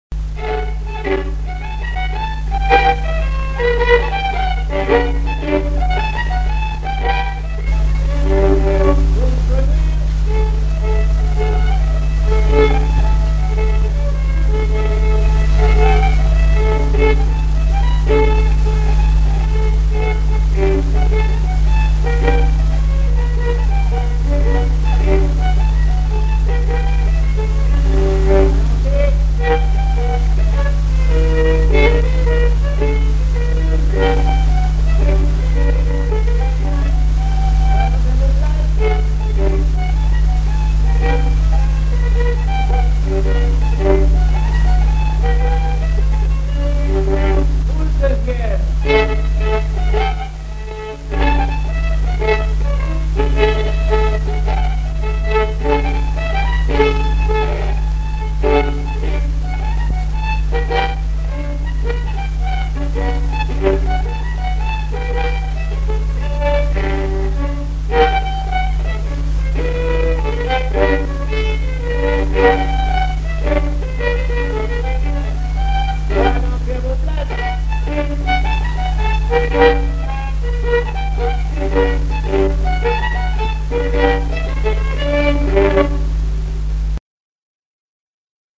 danse : quadrille : poule
Répertoire de violoneux
Pièce musicale inédite